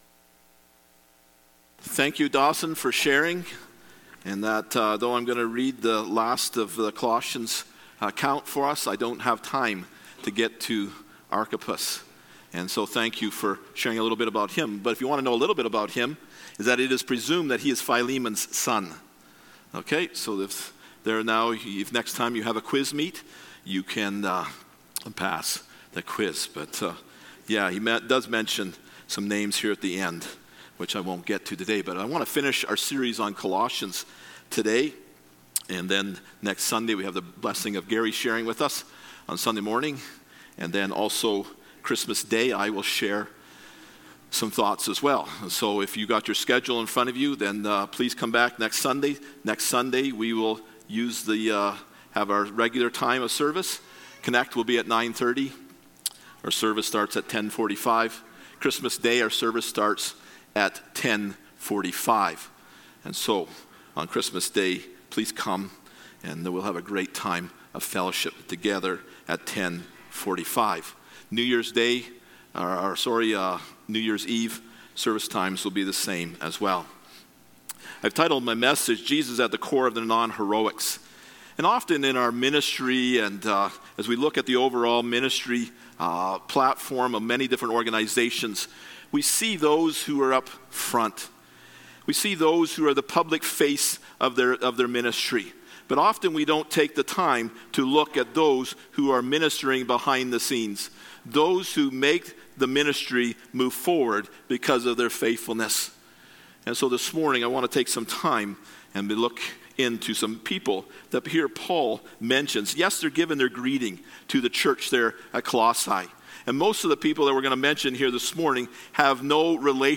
Passage: Colossians 4:7-18 Service Type: Sunday Morning « Jesus